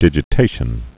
(dĭjĭ-tāshən)